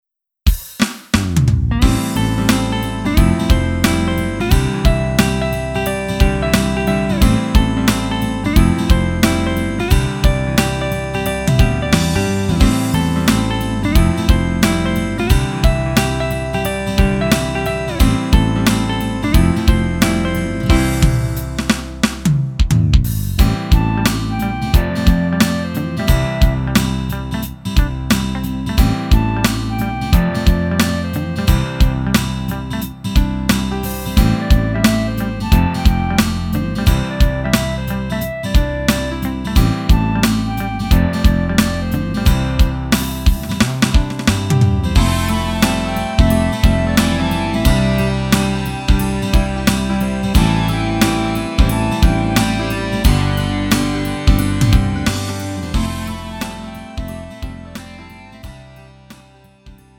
음정 -1키 4:43
장르 구분 Lite MR